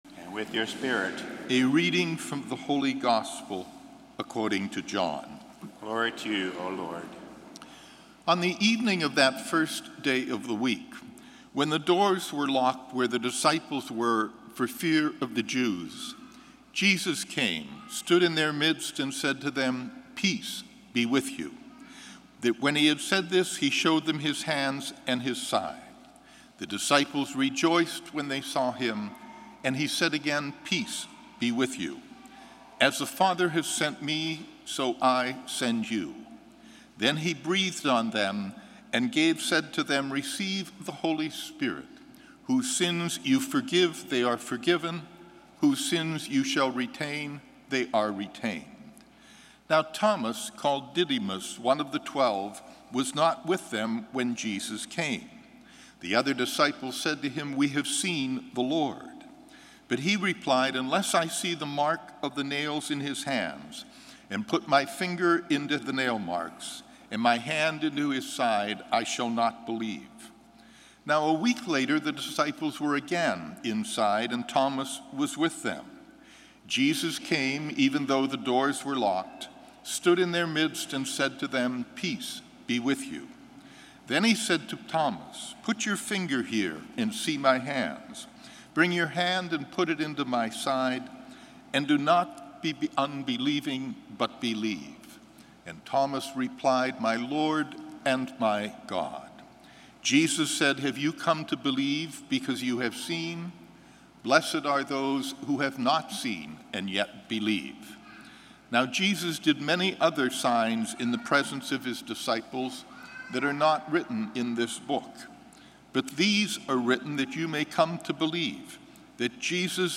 Gospel & Homily April 3, 2016